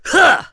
Roman-Vox_Attack4.wav